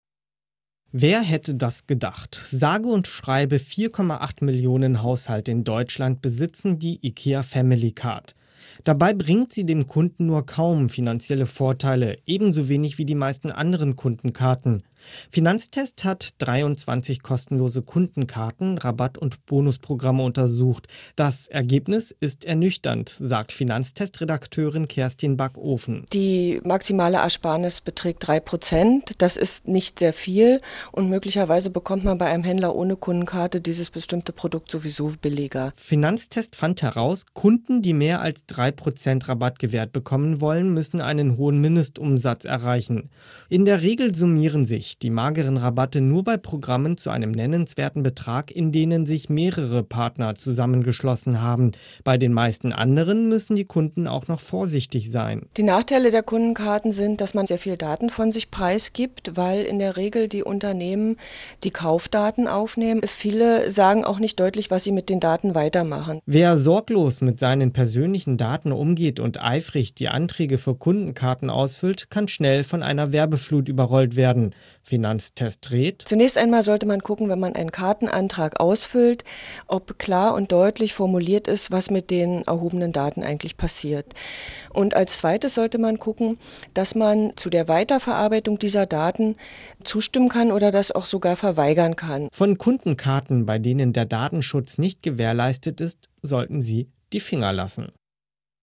O-Ton: Geringer Nutzen